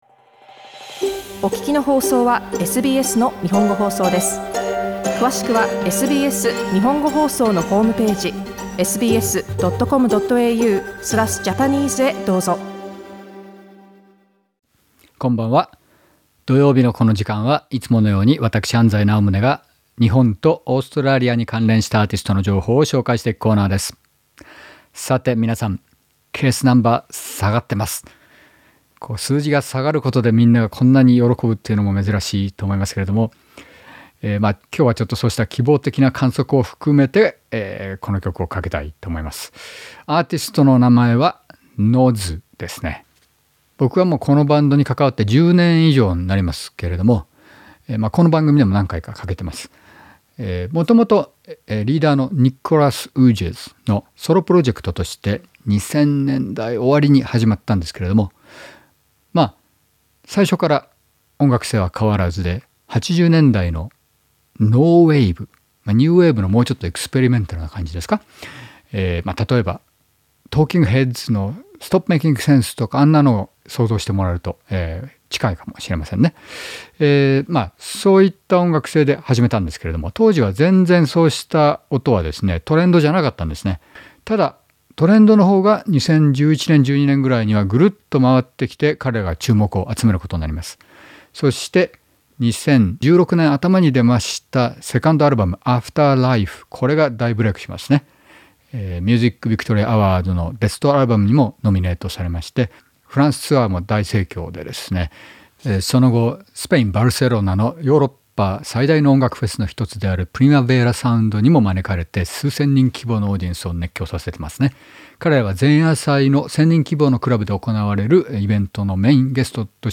彼らの音楽のジャンルは「ヒートウエーブ」だそうです。